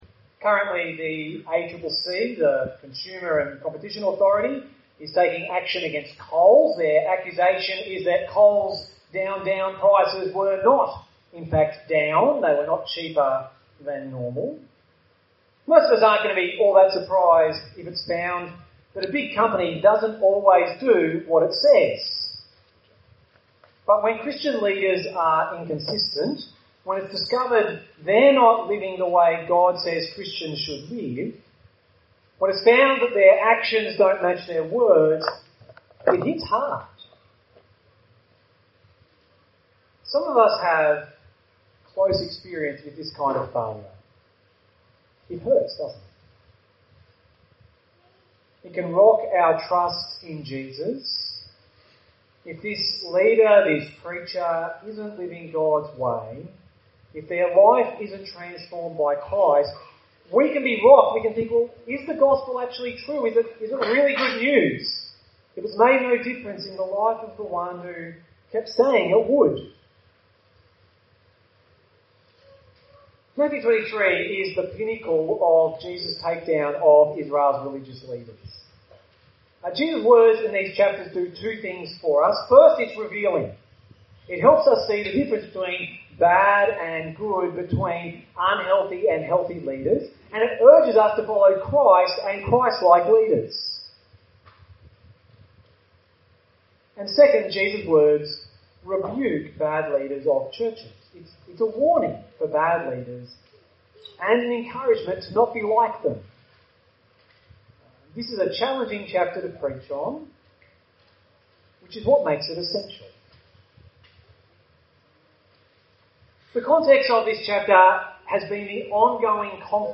Note: due to techincal issues, the audio quality is lower than usual.